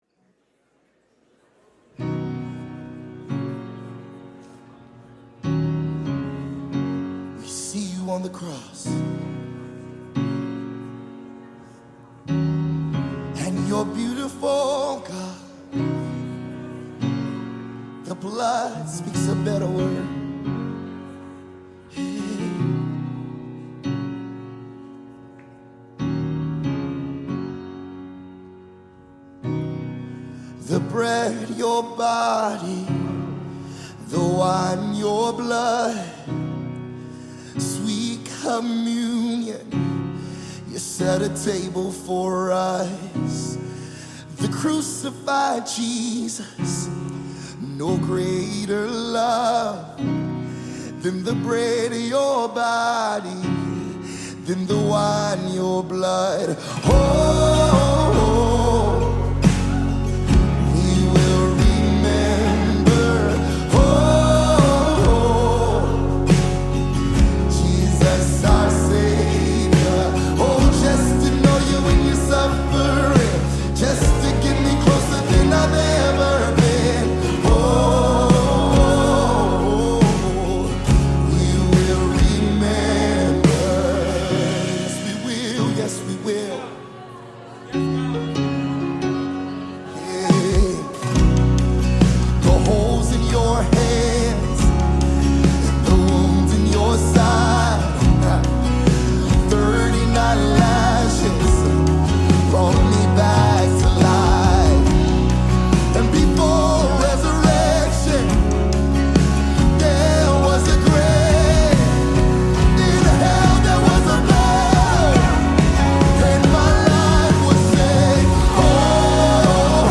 The prayer and worship Christian team perform the song
sings a soul-lifting song on a live stage